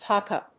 発音記号
• / ˈpɑkʌ(米国英語)
• / ˈpɑ:kʌ(英国英語)